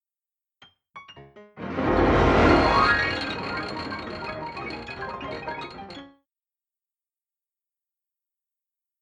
ニュートリノや筑波の研究施設から放たれた光電子を、スーパー・カミオカンデの光電子増倍管で捉えた際のデータを使い、MaxMSPのPatchを組んで50以上あるデータ全てをサウンドに変換した。
実際に起こる現象の実時間単位では、nsが基準になっており、1000,000,000Hzの音波になってしまい、可聴域では実現出来ないため、1nsを20msに伸長したスーパースローで、音高、音量、定位等へ変換し、よく聴き慣れていて中立的なピアノの音色を使って可聴化した。